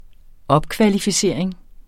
Udtale [ ˈʌbkvalifiˌseˀɐ̯eŋ ]